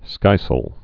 (skīsəl, -sāl)